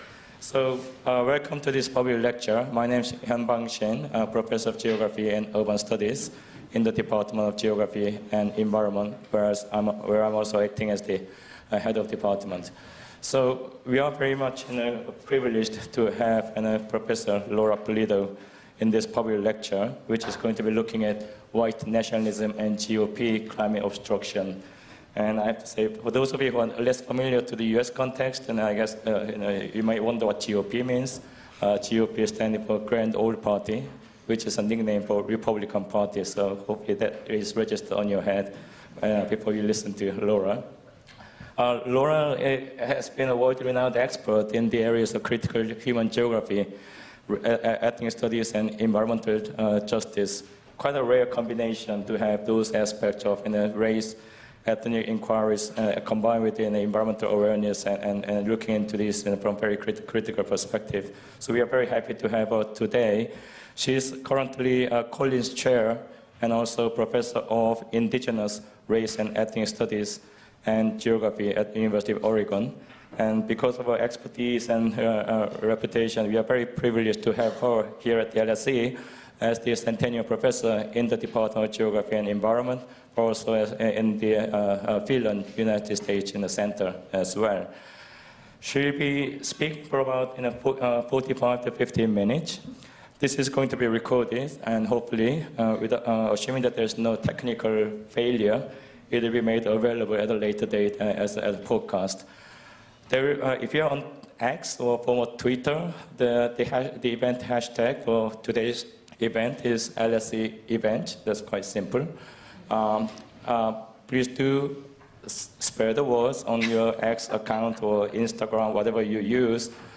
More about this event This event is the inaugural lecture in the Department of Geography and Environment's new Sustainability Public Lecture Series .